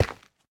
Minecraft Version Minecraft Version 25w18a Latest Release | Latest Snapshot 25w18a / assets / minecraft / sounds / block / basalt / step5.ogg Compare With Compare With Latest Release | Latest Snapshot
step5.ogg